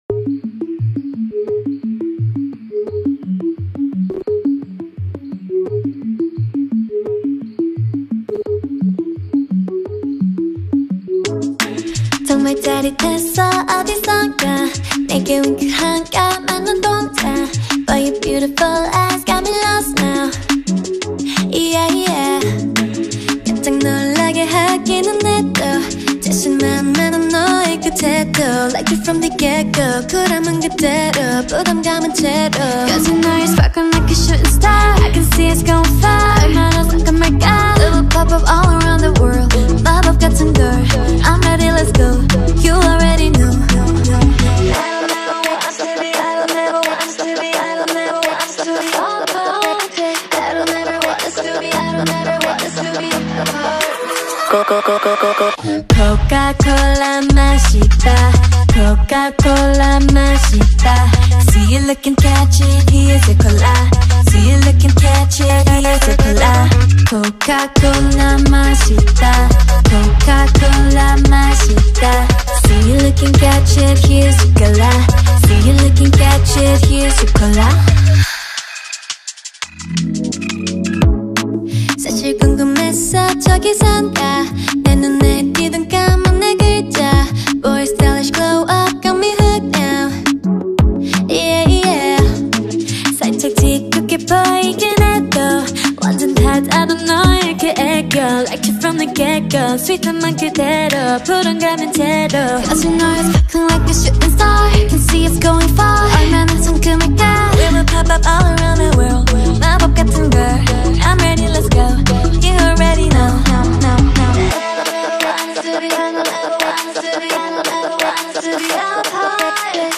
Скачать музыку / Музон / Корейская K-POP музыка 2024